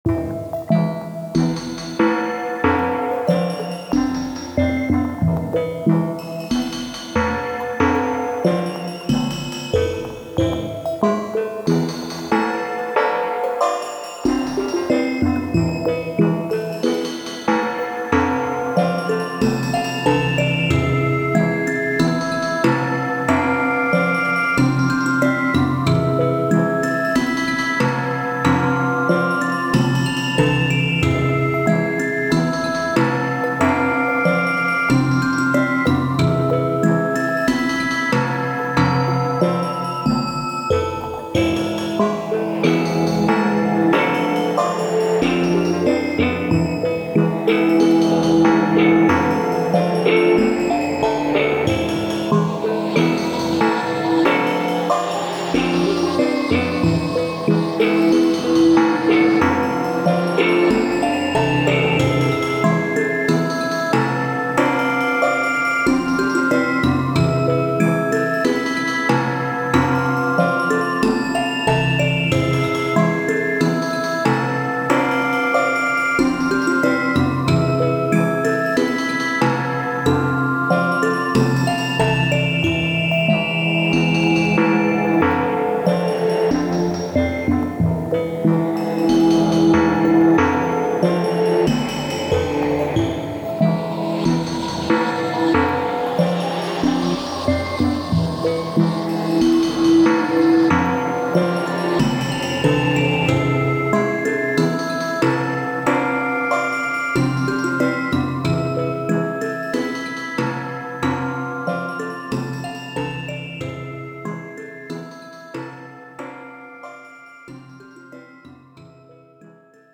怪しい商店をイメージした楽曲。
タグ: ダラダラ 不思議/ミステリアス 不気味/奇妙 怪しい 暗い コメント: 怪しい商店をイメージした楽曲。